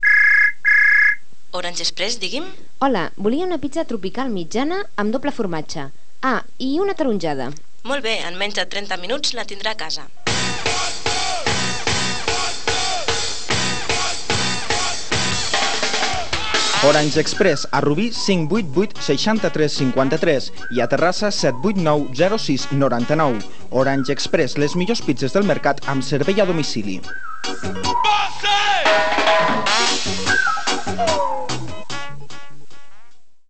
Anunci publicitari